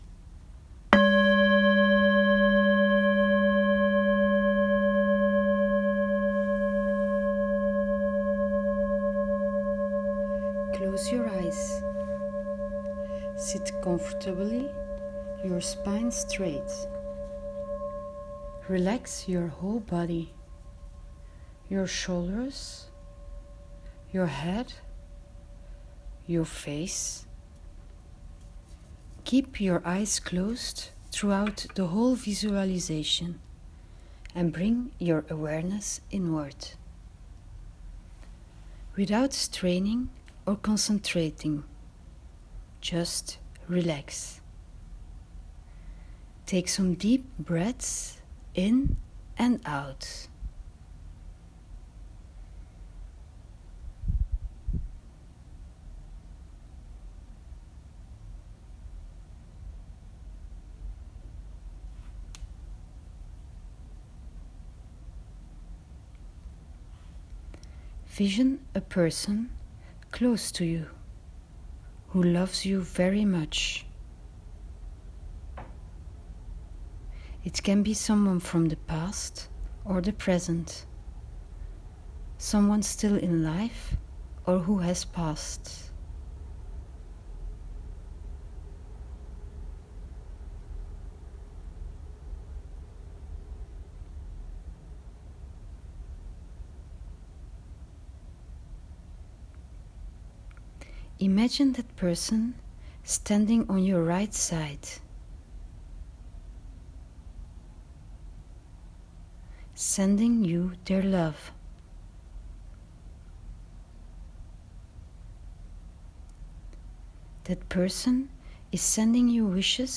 Meditation for Monday 18/12